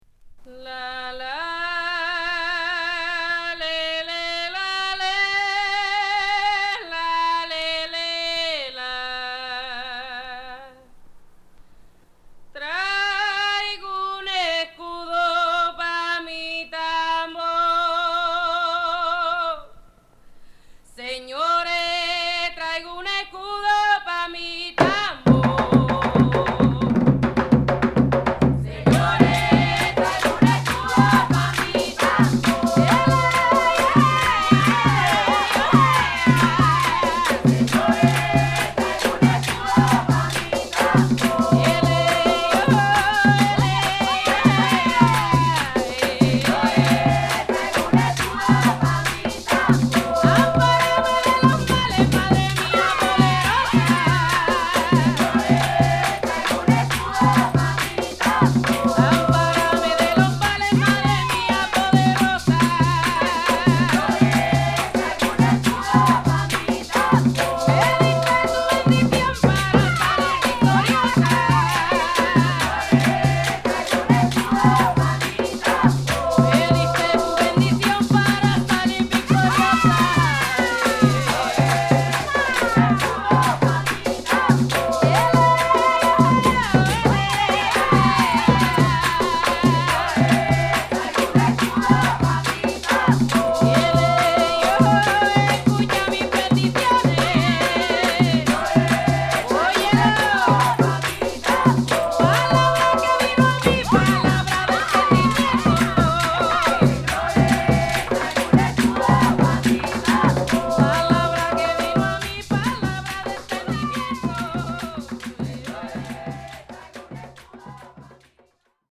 打楽器、チャント、ダンス…